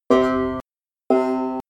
Here, the other strings are left open (undamped). I chose one typical pluck for each tailpiece configuration.
Here are the same two plucks, this time cut off after 0.5 seconds:
Again, there is little difference below 1500 Hz.
The dramatic differences between the two set-ups are confined to the first 0.2 - 0.3 seconds of the pluck.